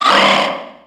Cri de Skelénox dans Pokémon X et Y.